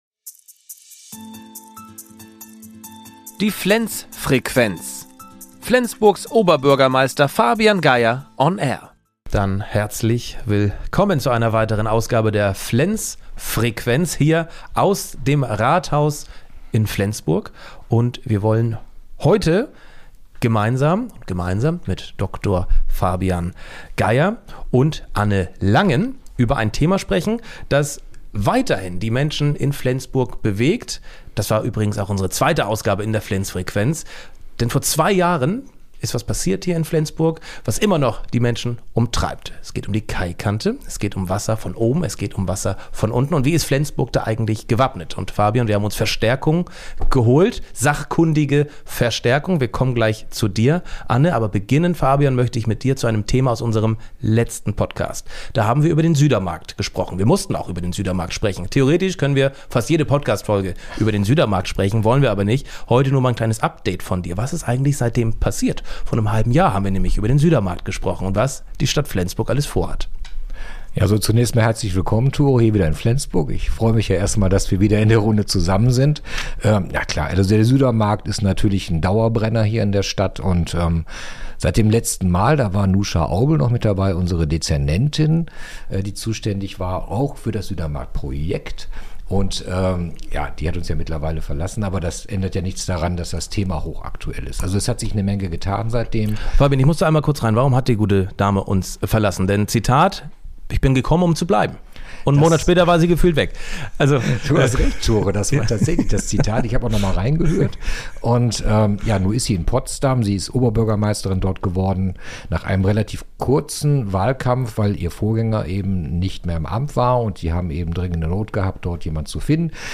Oberbürgermeister Fabian Geyer zieht eine Zwischenbilanz, wie sich der Südermarkt entwickelt.
Jetzt im Advent sendet der Oberbürgermeister natürlich auch eine kurze Weihnachtsbotschaft hinaus.
im Gespräch